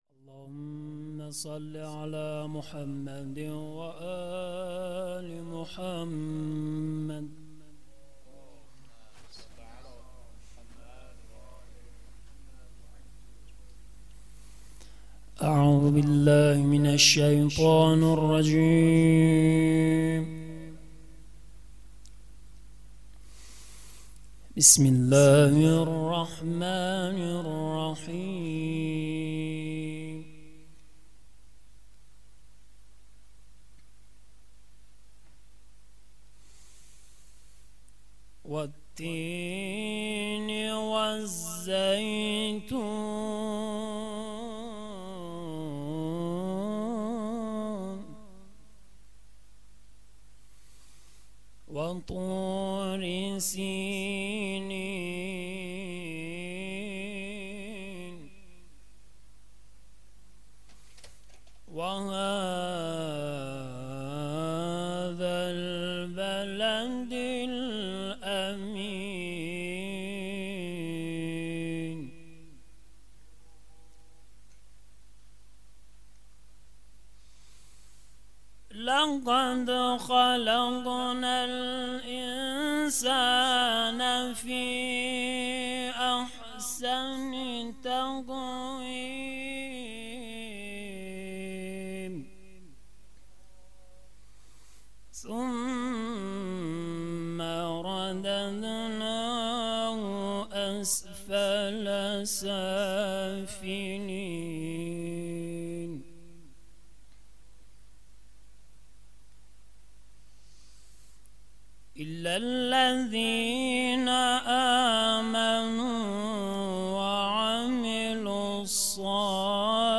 تلاوت قرآن کریم
مراسم هفتگی | 17 آبان ماه 1400